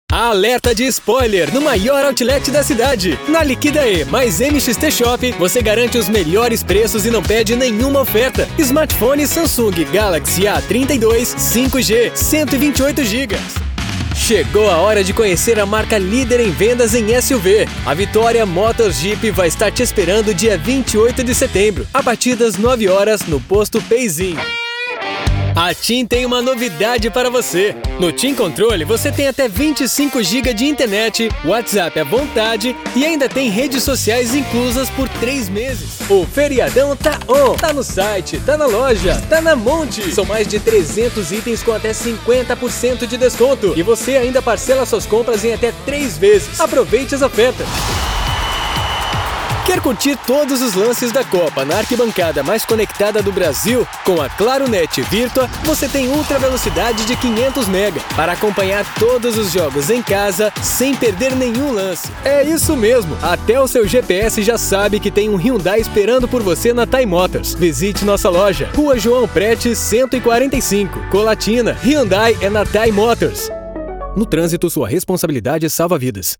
Masculino
Voz Varejo 01:16
Além de equipamentos profissionais devidamente atualizados, todas as locuções são gravadas em cabine acústica, resultando em um áudio limpo e livre de qualquer tipo de interferência.